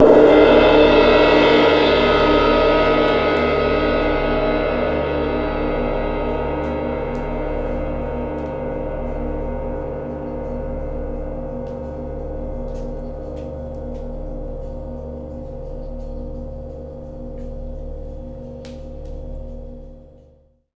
Track 02 - Gong OS.wav